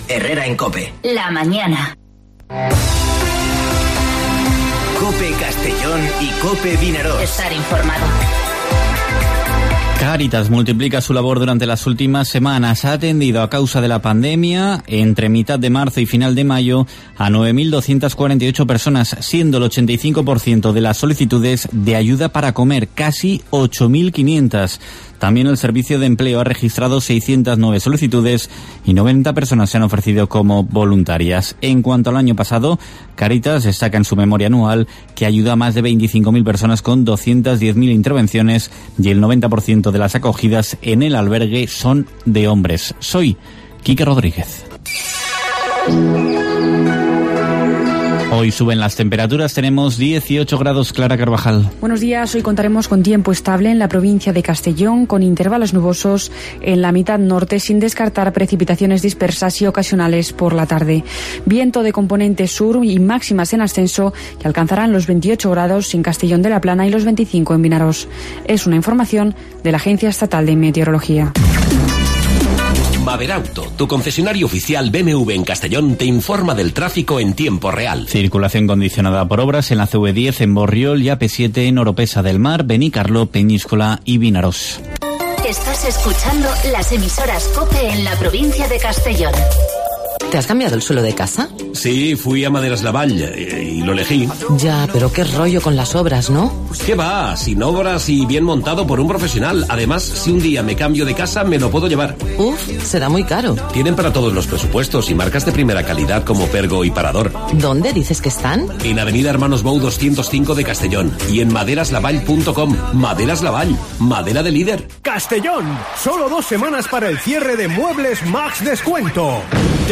Informativo Herrera en COPE en la provincia de Castellón (11/06/2020)